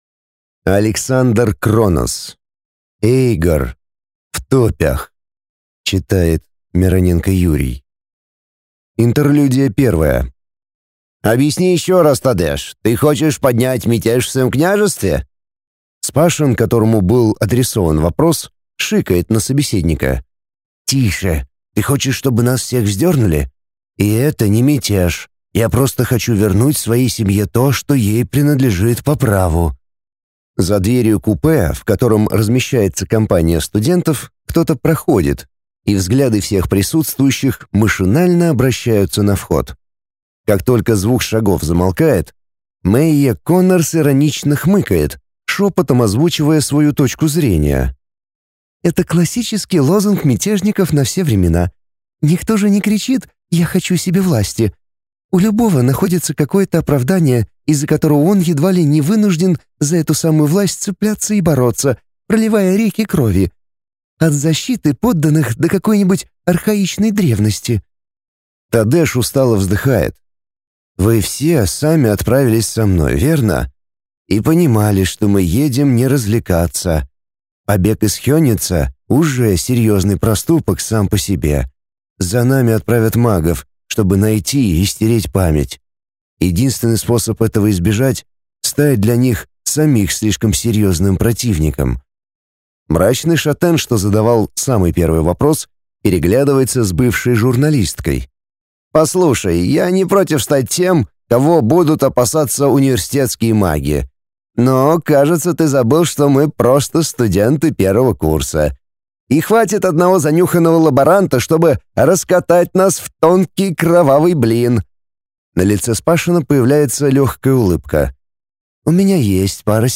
Аудиокнига Эйгор. В топях | Библиотека аудиокниг